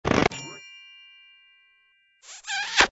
AA_heal_smooch.ogg